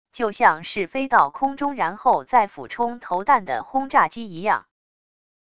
就像是飞到空中然后再俯冲投弹的轰炸机一样wav音频